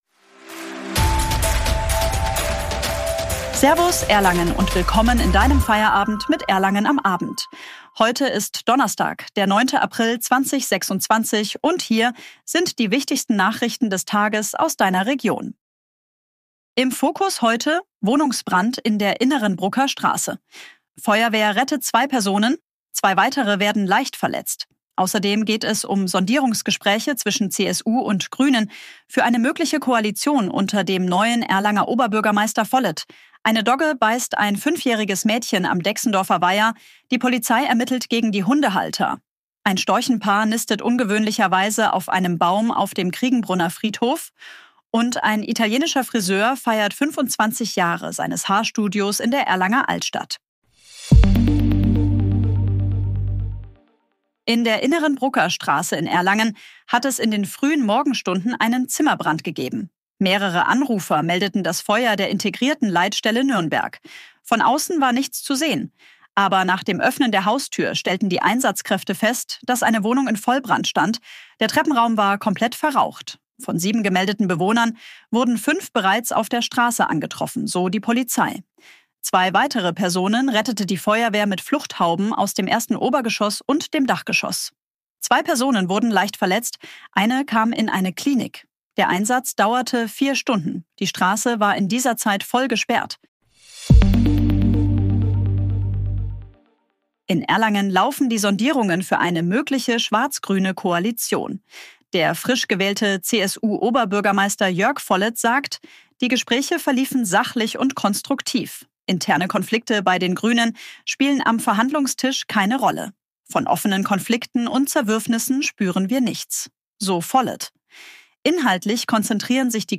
Guten Abend Erlangen! Willkommen zu deinem täglichen News-Update